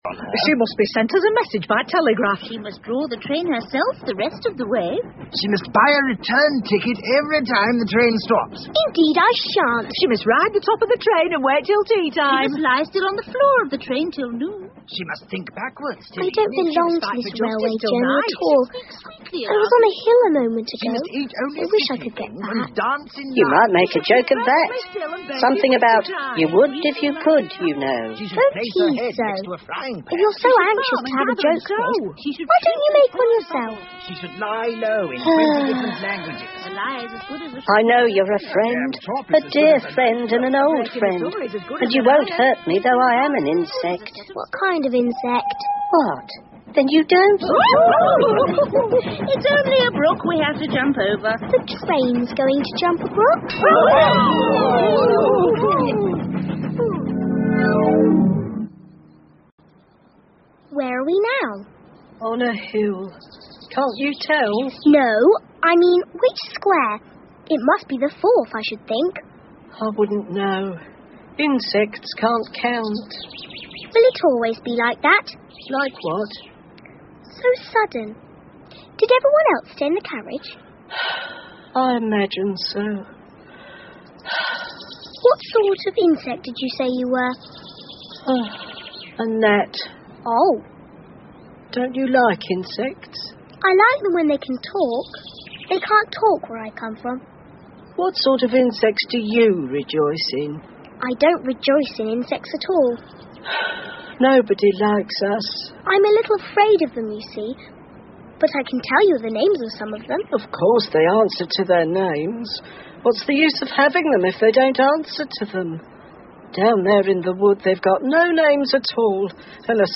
Through The Looking Glas 艾丽丝镜中奇遇记 儿童广播剧 6 听力文件下载—在线英语听力室